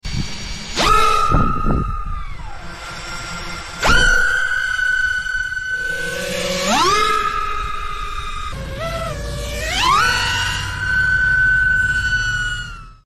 4S vs 6S ⚡ Feel the difference at takeoff!
smooth vs insane acceleration